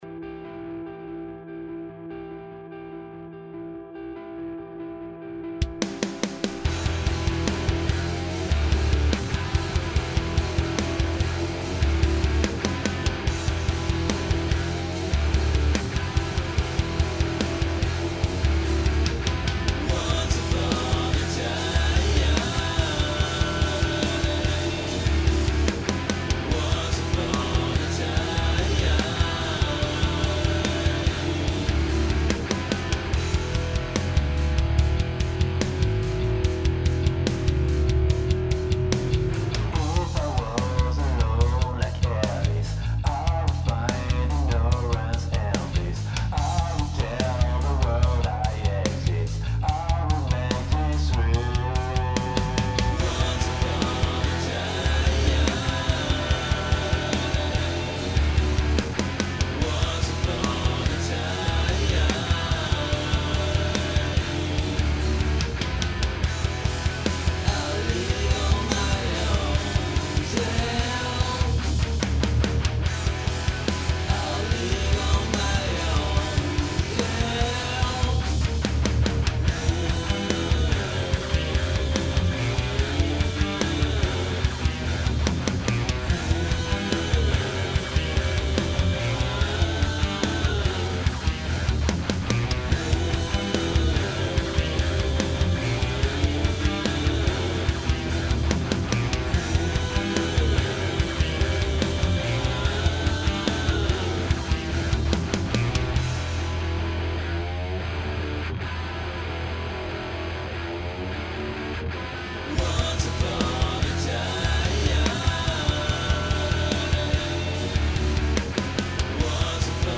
De mon coté, un petit test de prod façon Alice In Chains,
C'est quoi le bruit de fond qu'on entend en permanence et qui est super casse-couille genre sifflement ?
T'as trop forcé sur l'eq des toms ou tu les as trop sorti du kit écoute à 0.18/0.20.
Ta mise à plat gagnerait vraiment en faisant varier les niveaux.